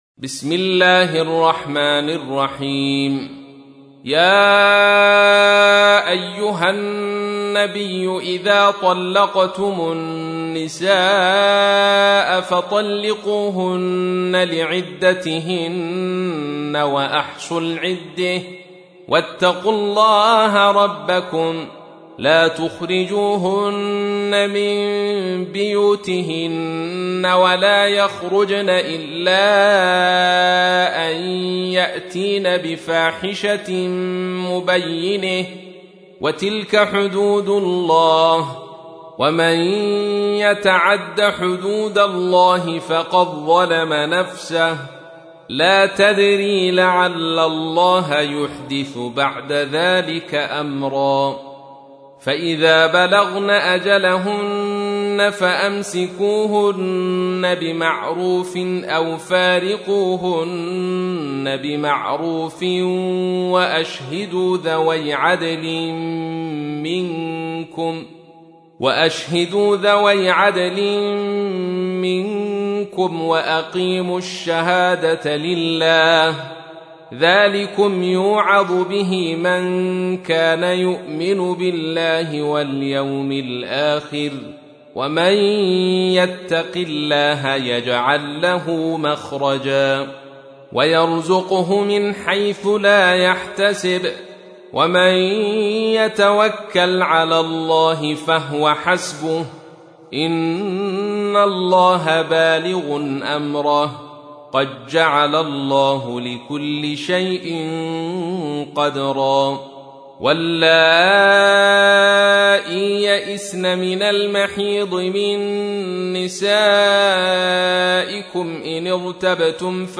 تحميل : 65. سورة الطلاق / القارئ عبد الرشيد صوفي / القرآن الكريم / موقع يا حسين